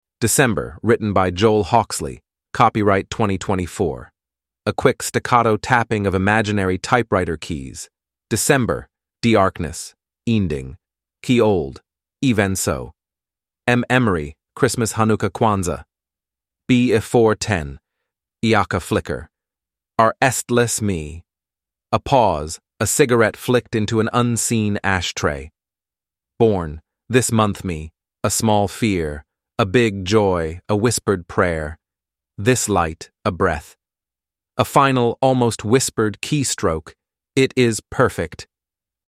(a quick, staccato tapping of imaginary typewriter keys)